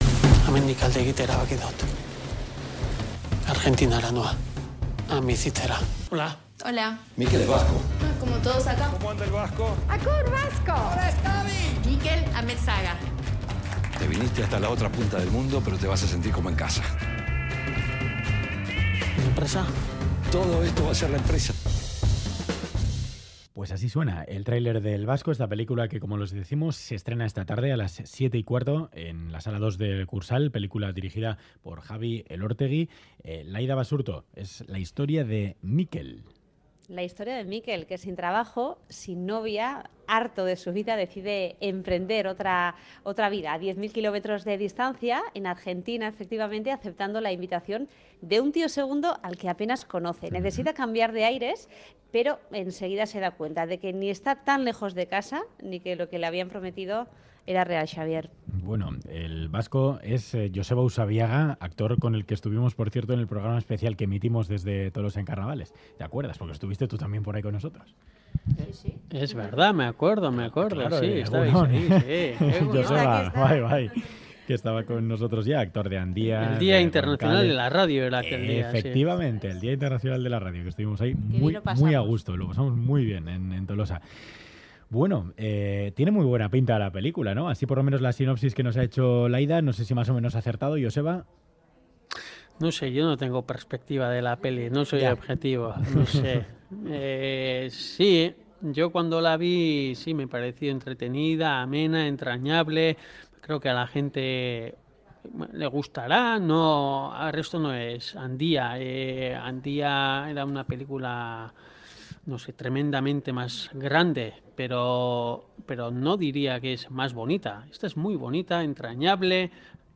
Trailer de la película El Vasco; entrevista